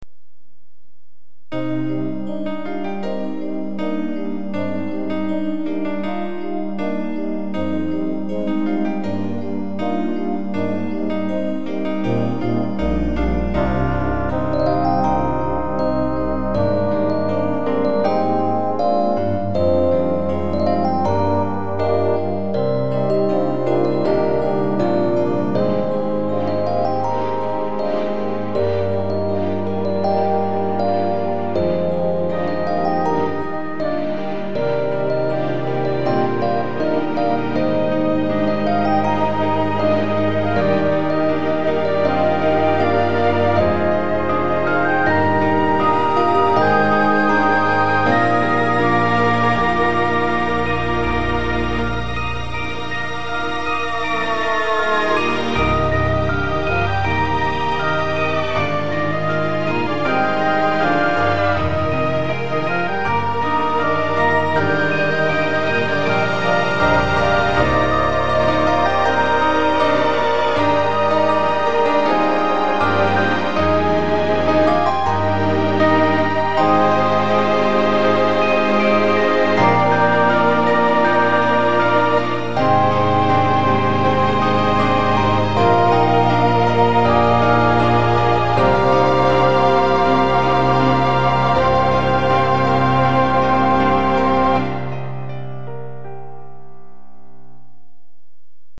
こちらはどちらかといえば水のような感じです。
インストゥルメンタル